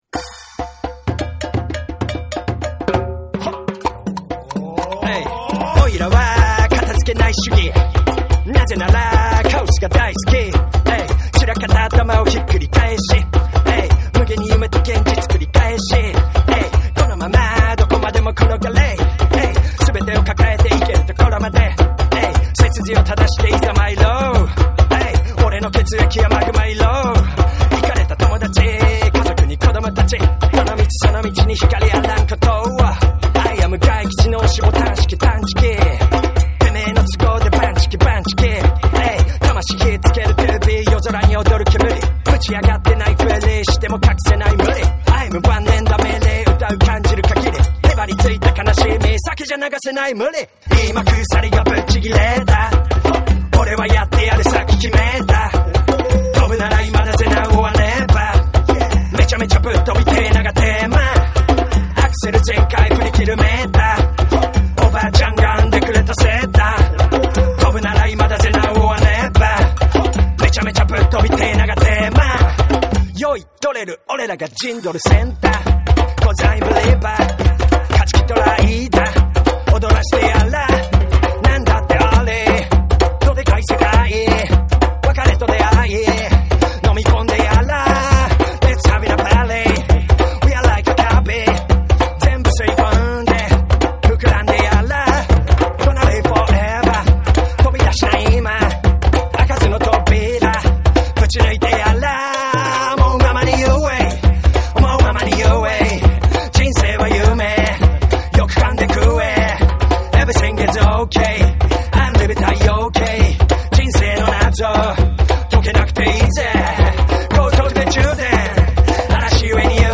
JAPANESE / BREAKBEATS / NEW RELEASE(新譜)